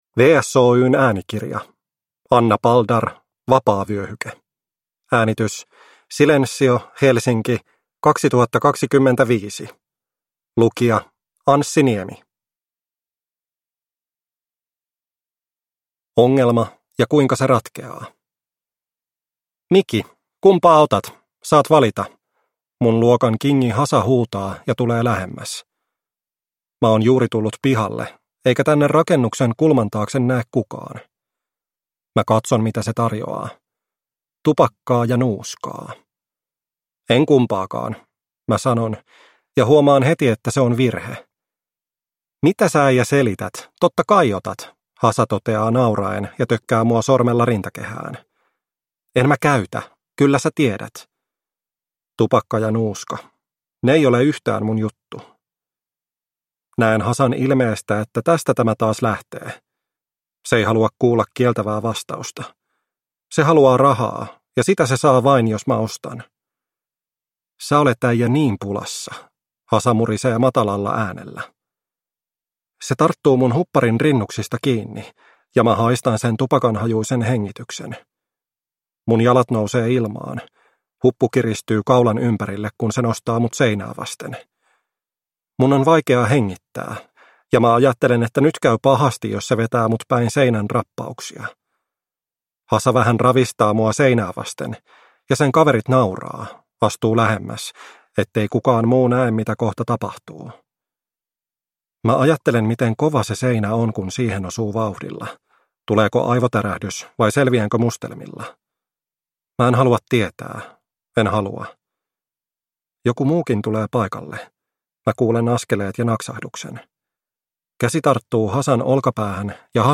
Vapaavyöhyke (ljudbok) av Anna Paldar | Bokon